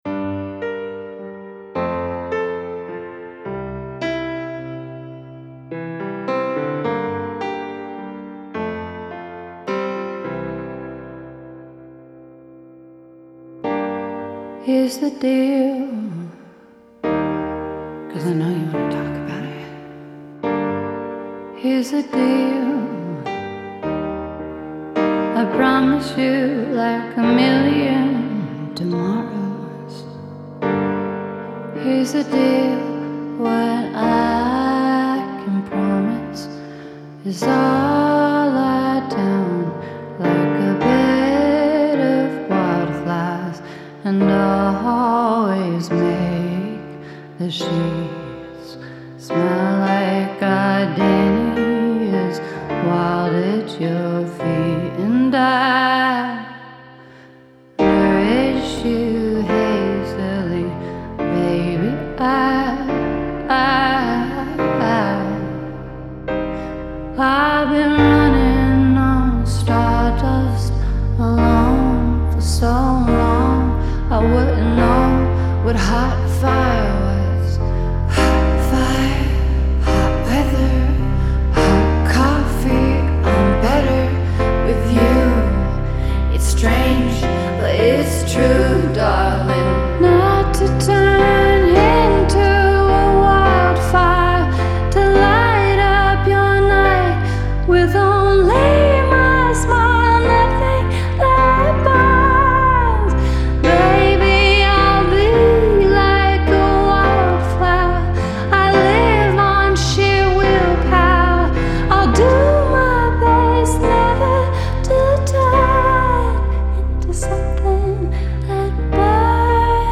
Genre : Alternative, Pop